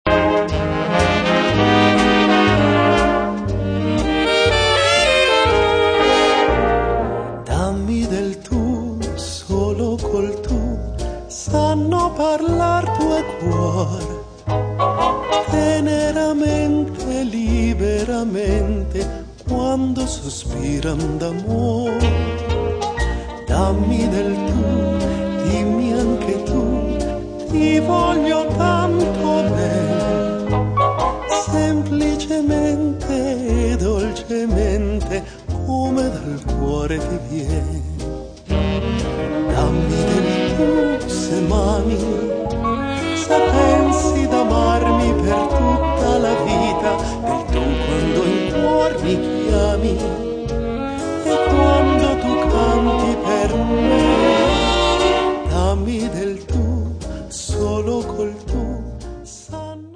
pianoforte
sax contralto, clarinetto
sax tenore
tromba
trombone
chitarra
contrabbasso
batteria